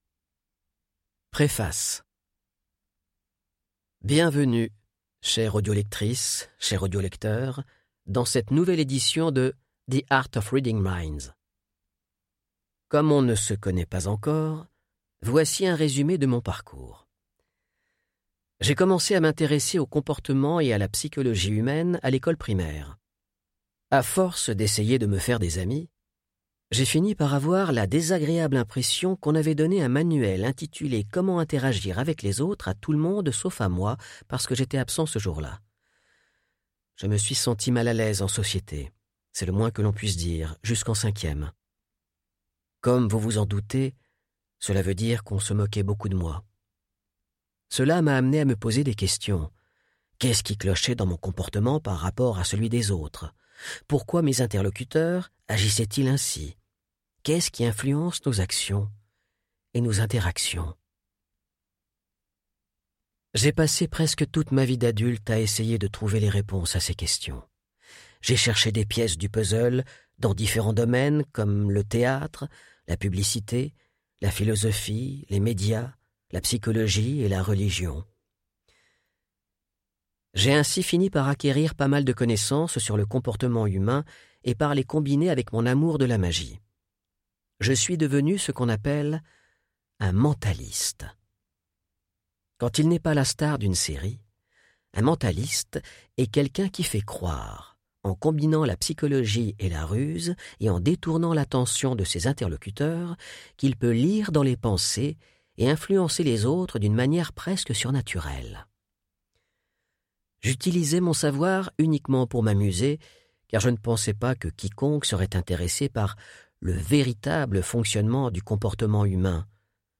Click for an excerpt - L'Art de lire dans les pensées - Les Secrets du plus grand mentaliste suédois ! de Henrik Fexeus